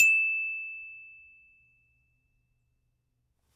Звук галочки в монтаже